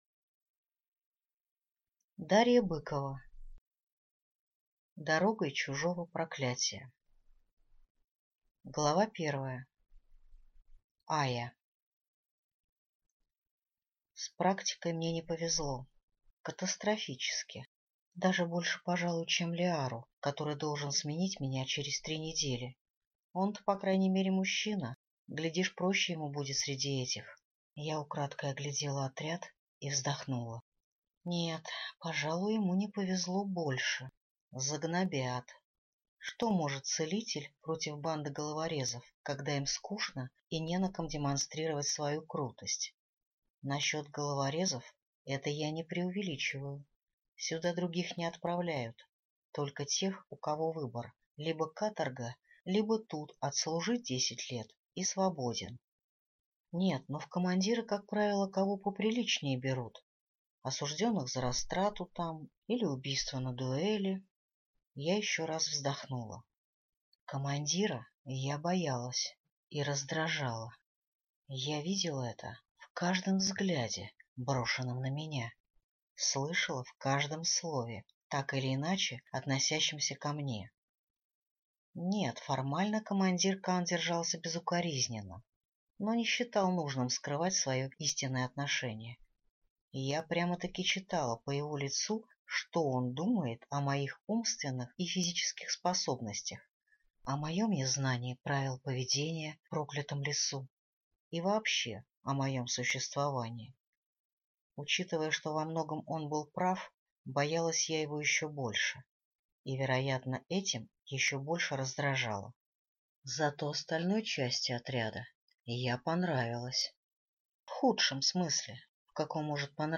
Аудиокнига Дорогой чужого проклятия | Библиотека аудиокниг